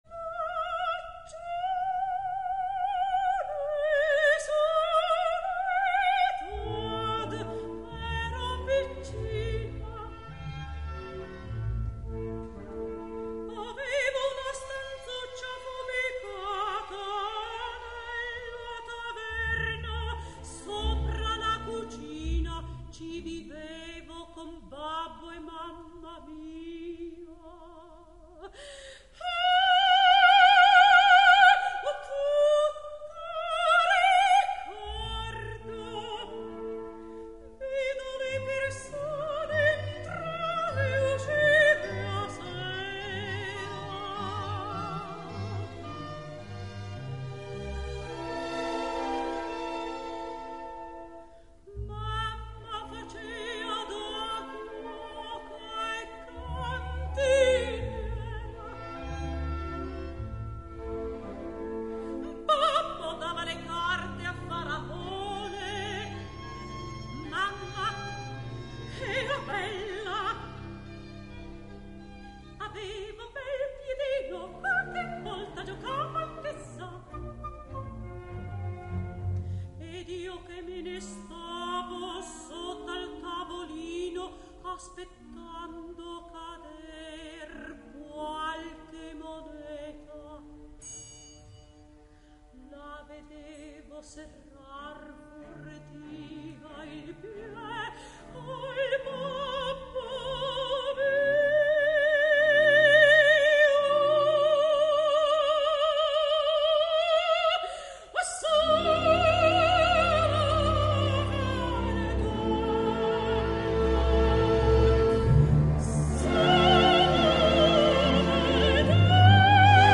Minnie Falconer [Sopran]